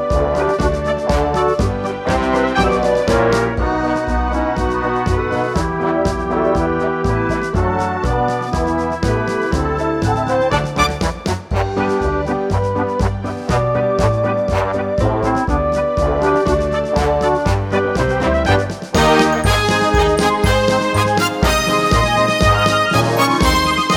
No Backing Vocals Oldies (Female) 2:58 Buy £1.50